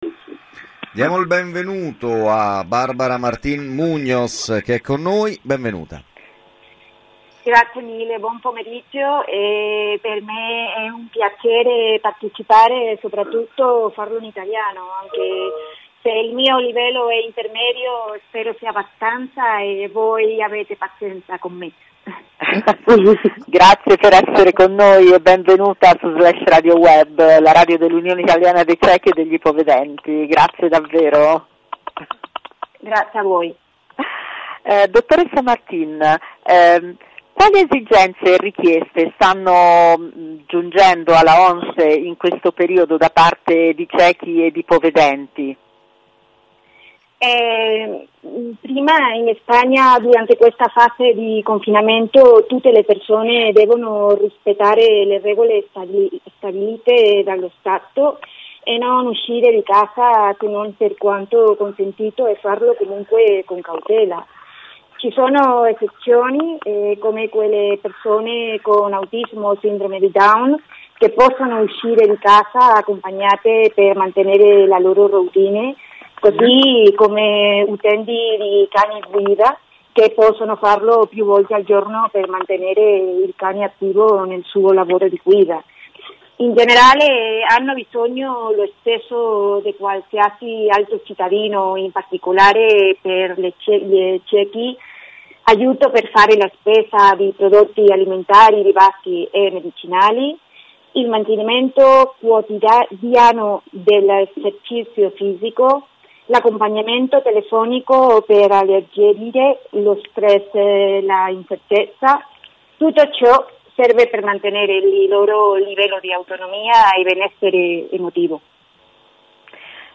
La Unión Italiana de Ciegos retransmite por radio la situación de las personas ciegas en España durante el estado de confinamiento